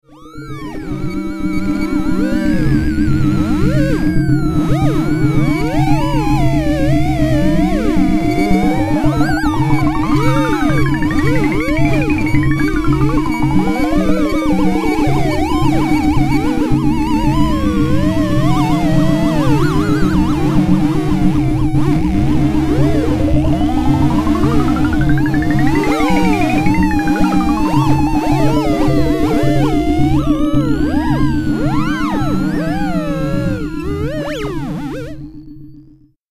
Synthecycletron is a pedal-powered interactive sound exhibit that allows anyone to create improvised music by pedaling a bike and moving their bodies to shape the sounds.
When a person pedals a bike, they will generate energy which powers the synthesizer components creating patterns of sounds. When they or someone else wave their hands in front of the Theremin Pedestals, pitch bending electronic sounds are generated from the Theremin synthesizers.
What they will hear will be improvised sound art.